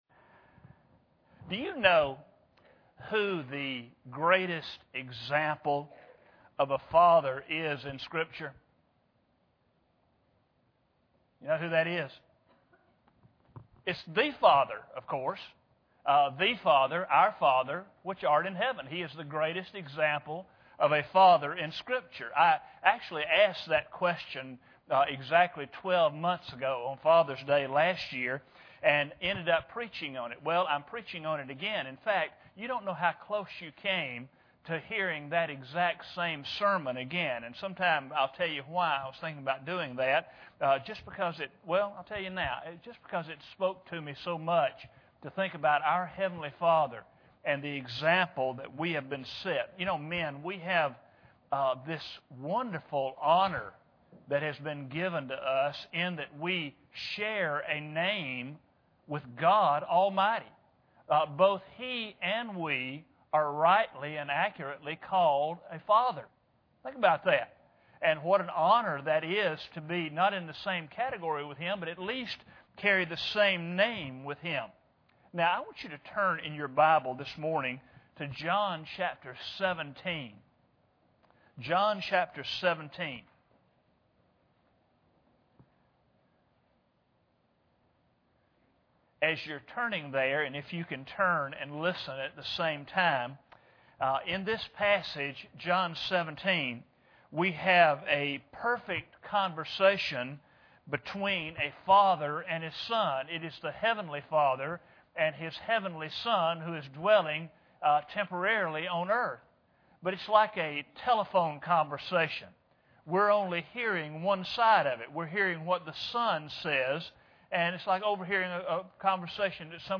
John 17:1-26 Service Type: Sunday Morning Bible Text